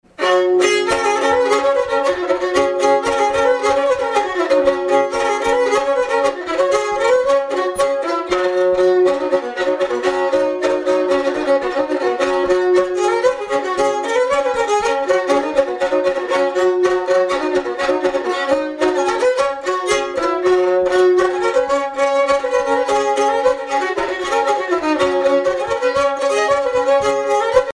danse : pas d'été
Pièce musicale inédite